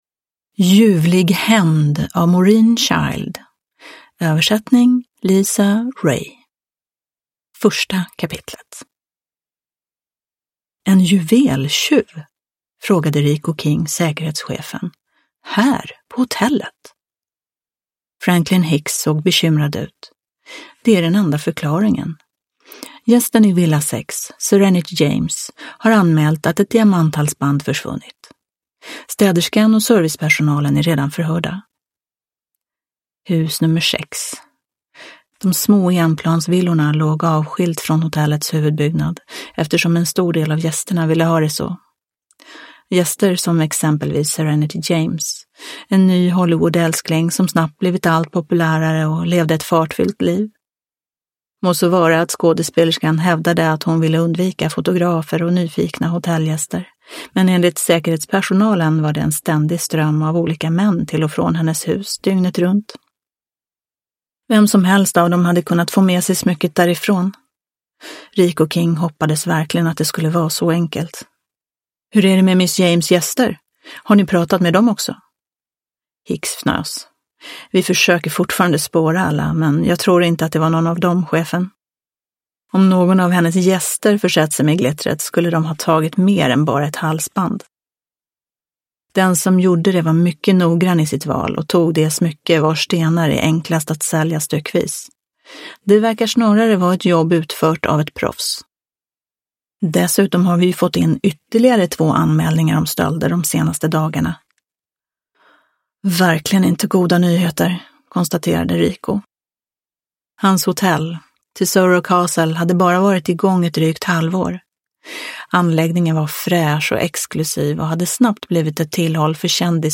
Ljuvlig hämnd – Ljudbok – Laddas ner